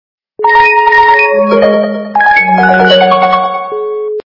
Звук для СМС - Sony Ericsson K600 Звук Звуки Звук для СМС - Sony Ericsson K600
При прослушивании Звук для СМС - Sony Ericsson K600 качество понижено и присутствуют гудки.